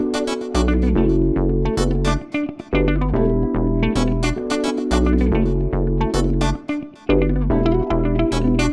35 Backing PT2.wav